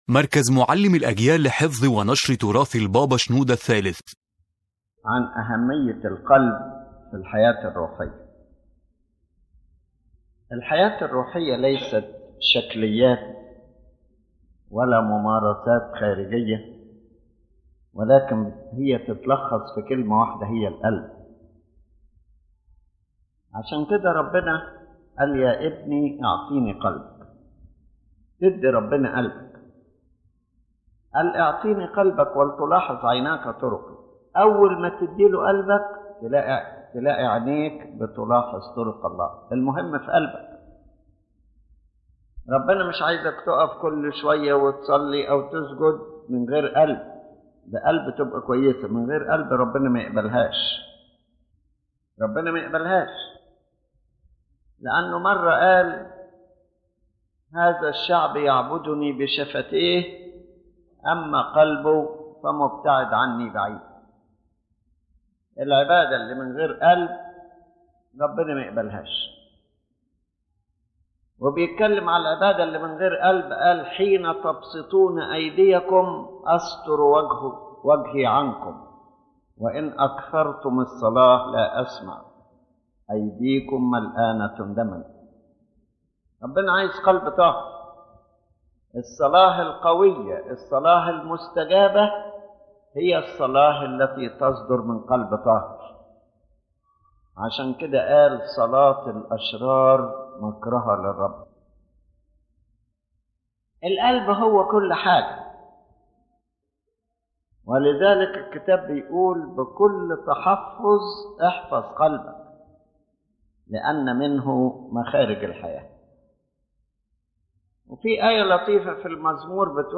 This lecture emphasizes that the essence of spiritual life is the heart, not outward practices or external appearances.